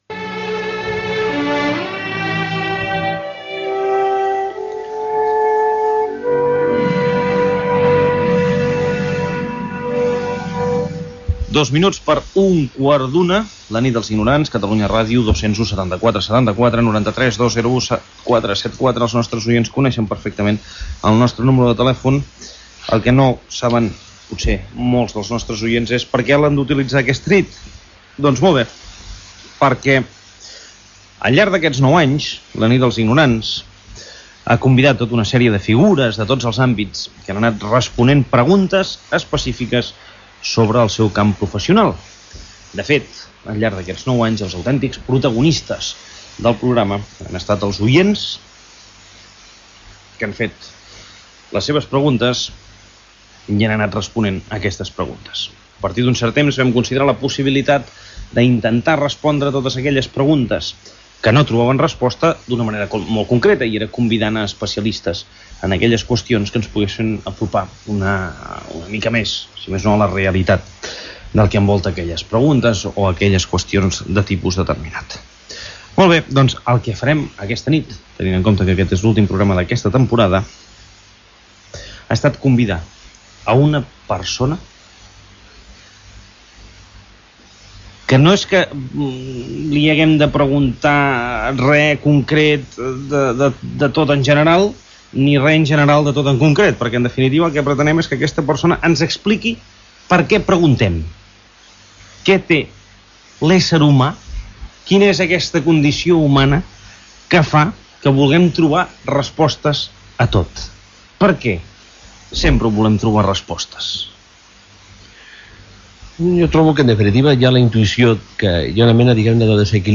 Gènere radiofònic Participació